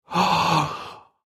Звуки удивления
Мужское удивление ах